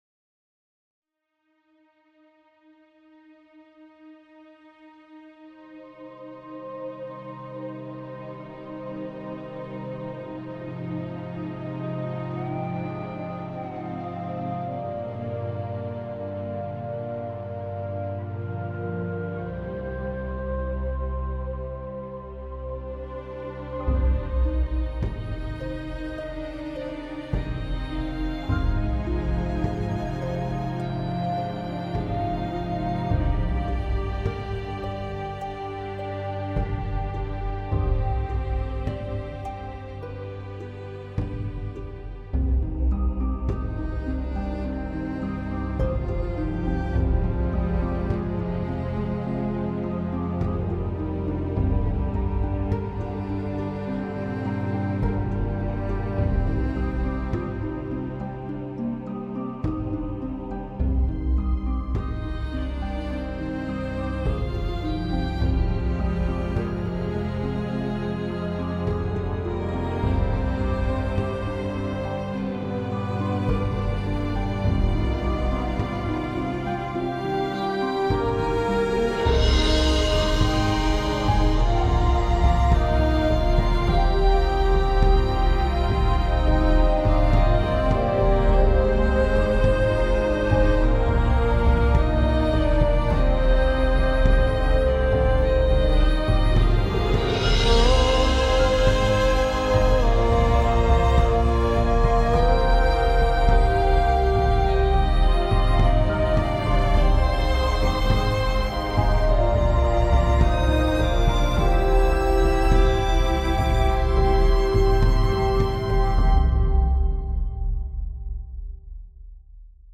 This commitment to your uninterrupted experience means no sudden advertising cuts will disturb your meditation, sleep, or relaxation sessions.